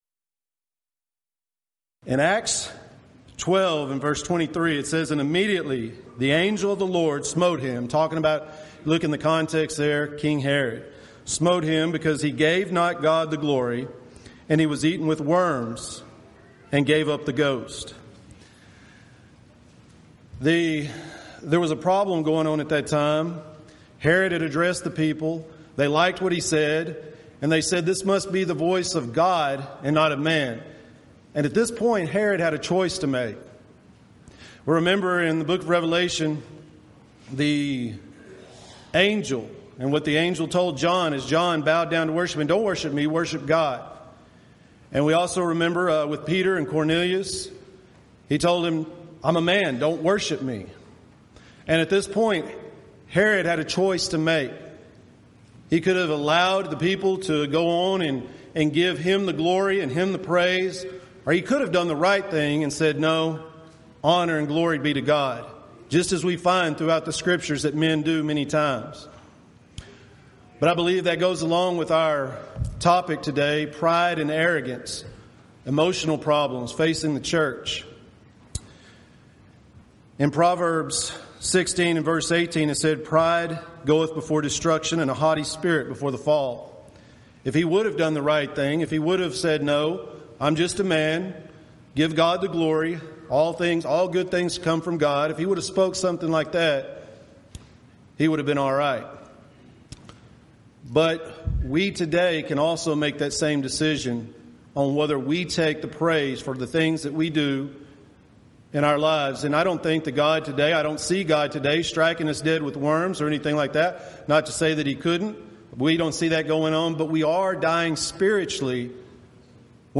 Event: 6th Annual Back to the Bible Lectures Theme/Title: Emotional Issues Facing the Church
lecture